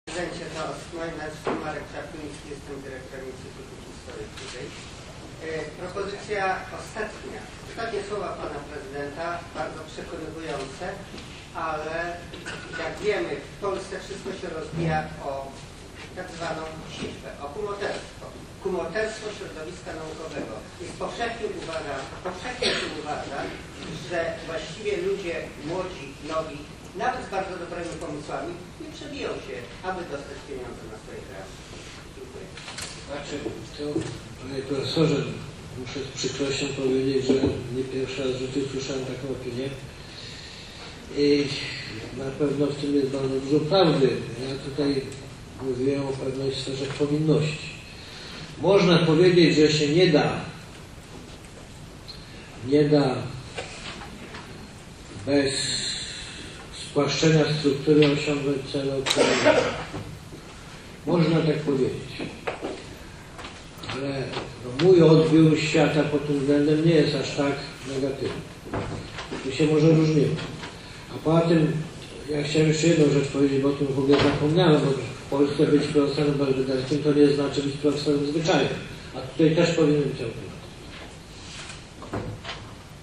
Po wykładzie Prezydent odpowiedział na pytania pracowników uczelni.